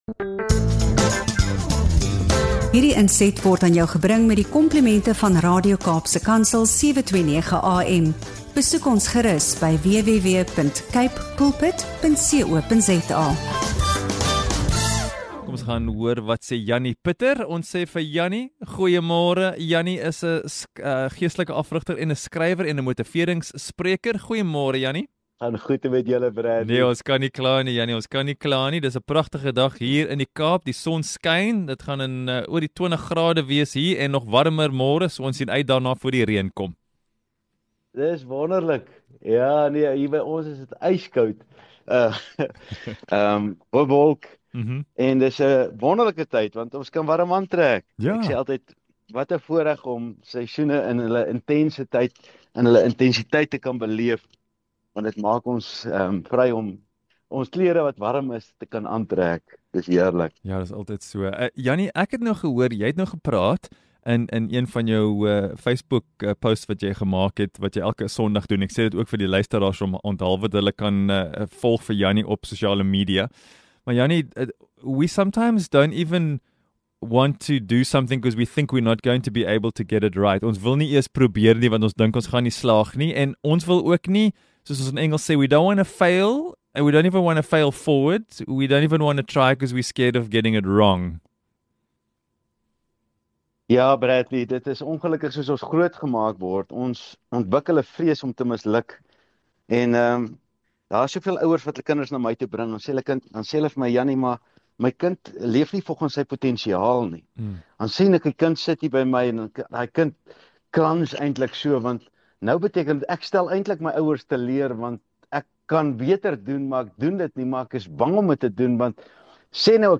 In hierdie insiggewende gesprek